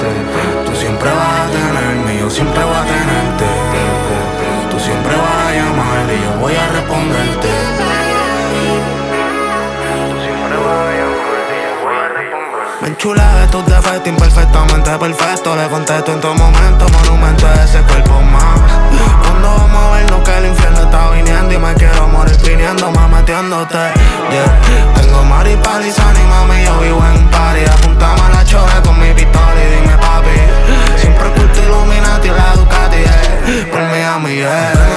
Urbano latino
Жанр: Латино